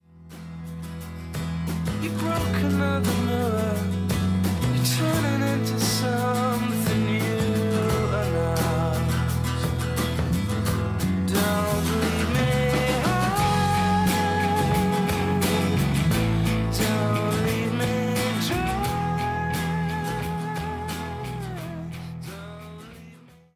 Tónica: Mi